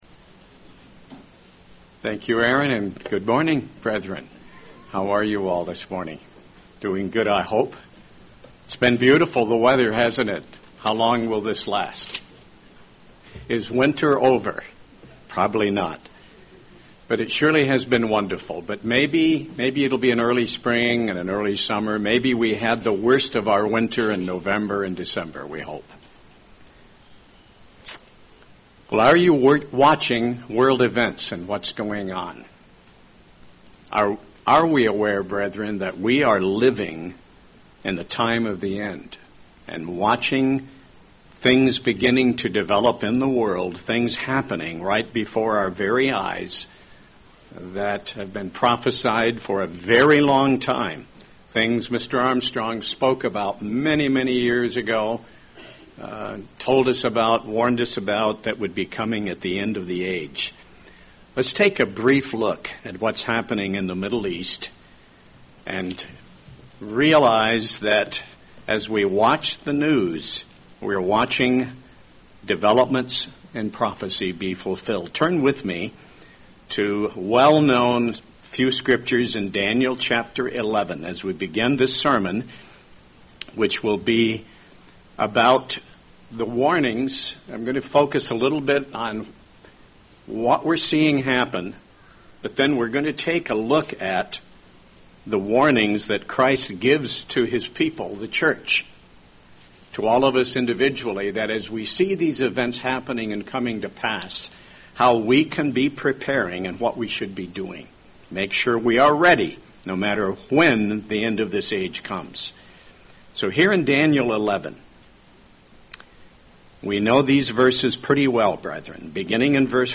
Given in Hickory, NC
UCG Sermon Studying the bible?